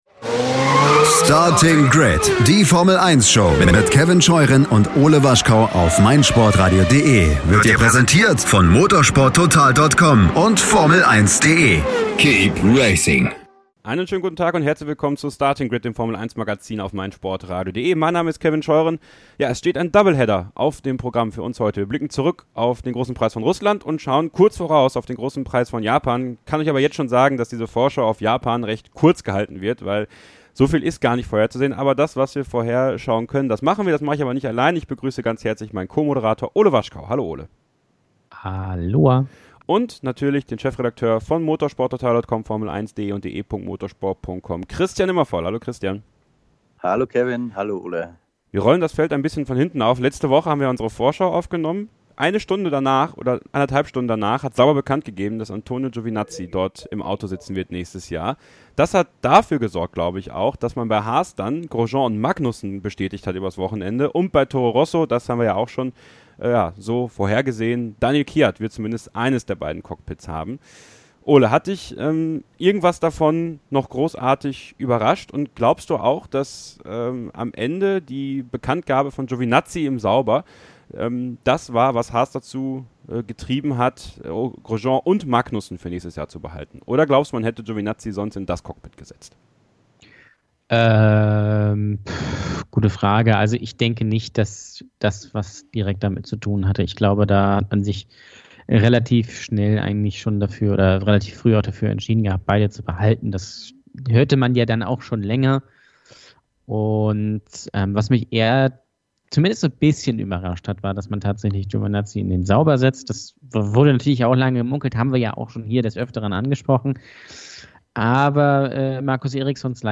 Eine hitzige Diskussion.